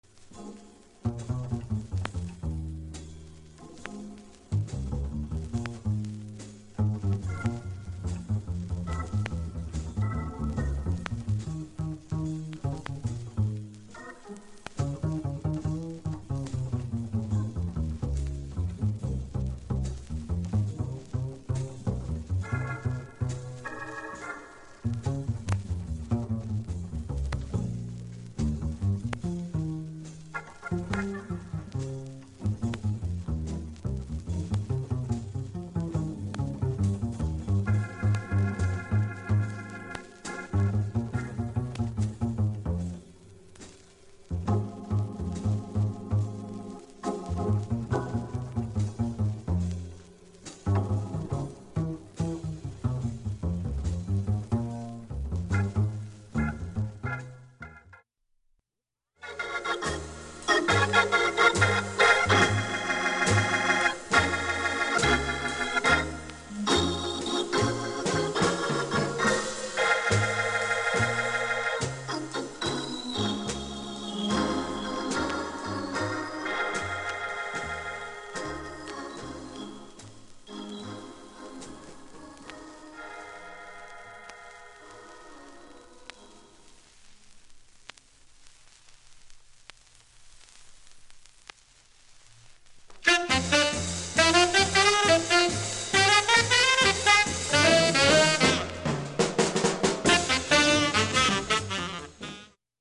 SOUL、FUNK、JAZZのオリジナルアナログ盤専門店
全曲試聴済みステレオ針での試聴
ＵＳＡ盤オリジナル MONO